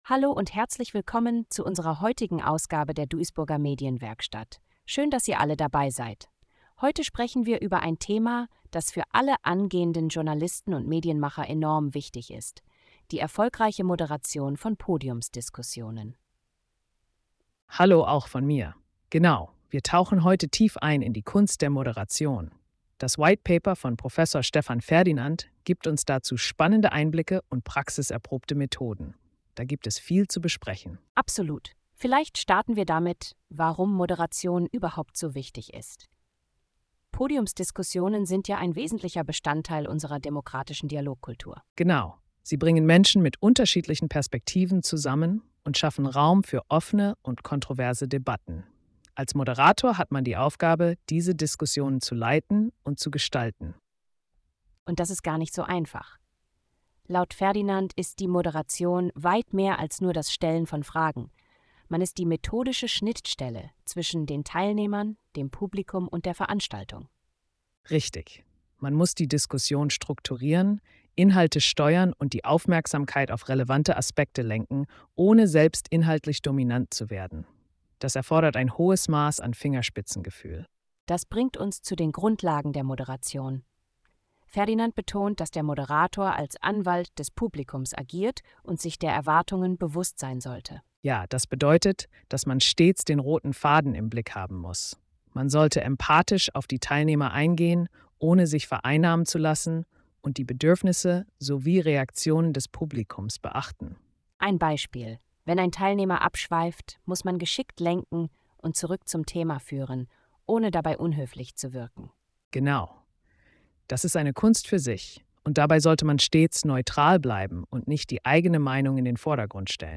Unsere KI-Hosts erklären die optimale Vorbereitung für erfolgreiche Podiumsdiskussionen.